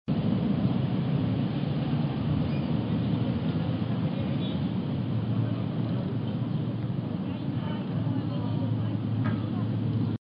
Pulse en cada imagen para escuchar un ruido de tráfico típico, atenuado por la correspondiente configuración de aislamiento.
a) ventana simple, vidrio 4 mm.
Ruido ambiental atenuado por la configuración de aislamiento fachada de ladrillo y ventana simple.